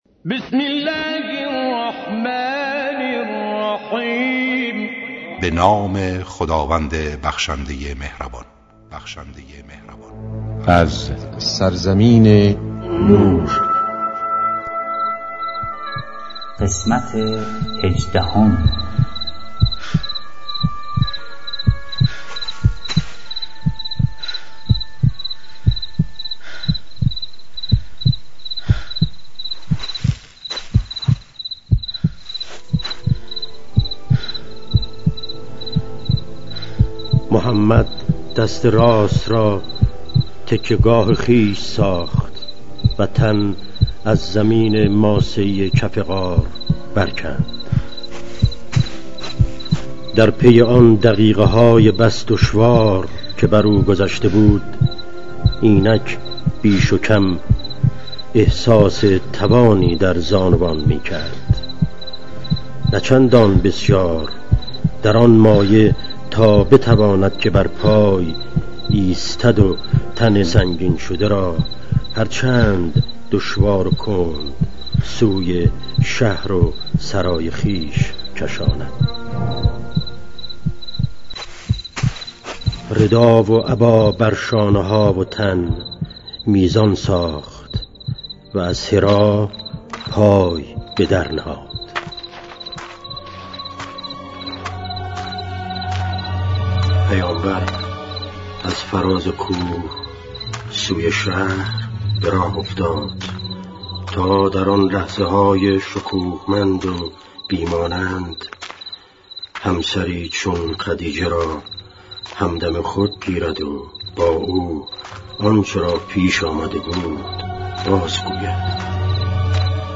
با اجرای مشهورترین صداپیشگان، با اصلاح و صداگذاری جدید